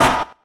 mech_hurt_2.ogg